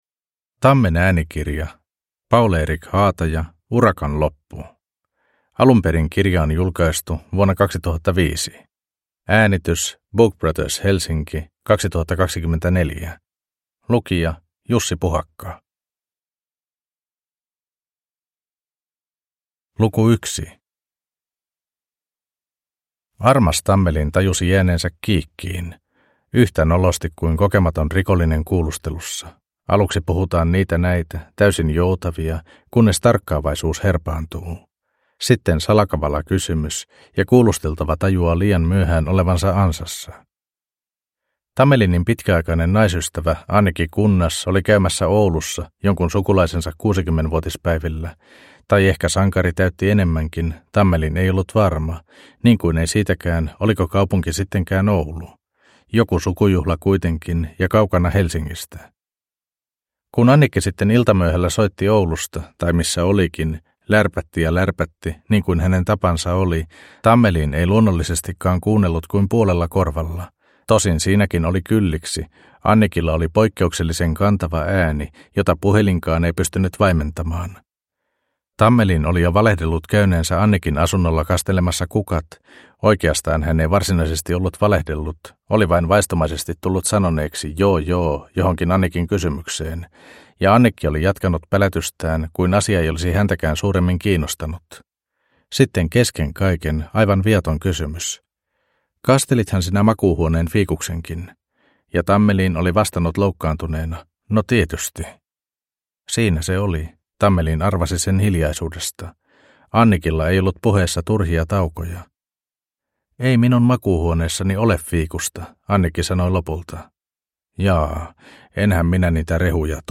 Urakan loppu (ljudbok) av Paul-Erik Haataja